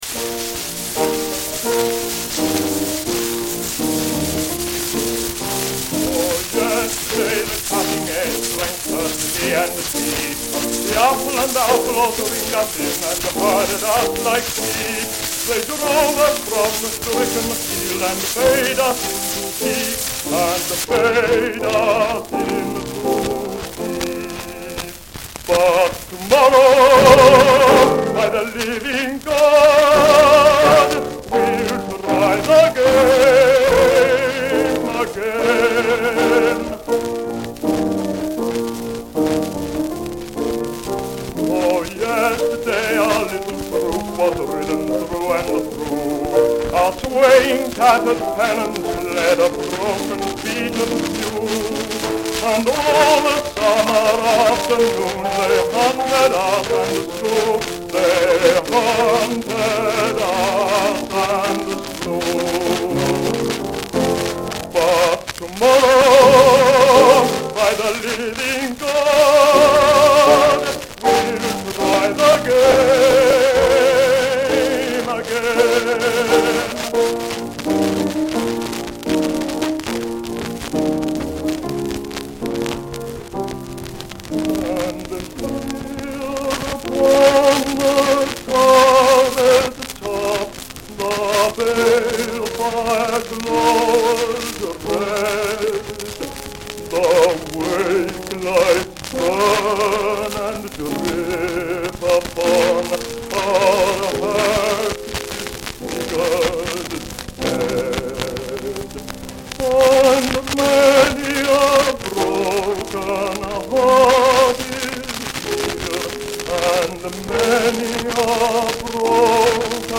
An original recording! So yes it is scratchy.